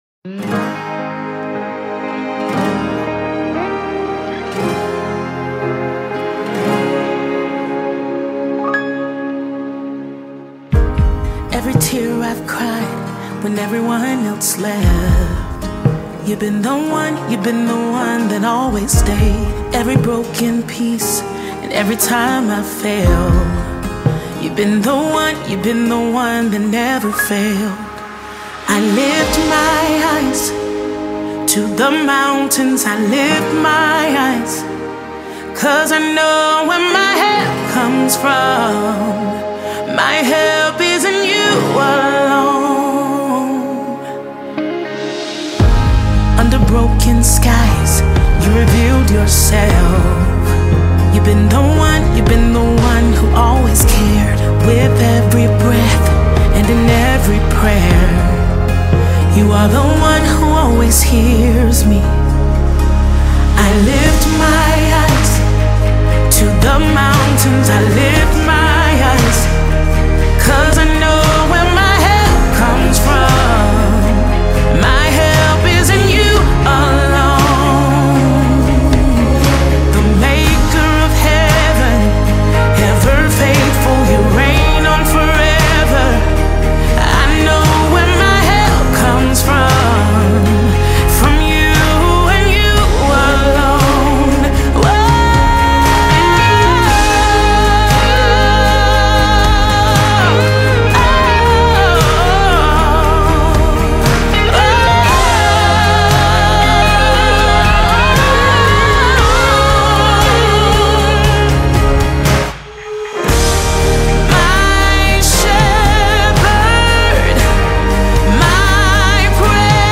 66 просмотров 147 прослушиваний 6 скачиваний BPM: 117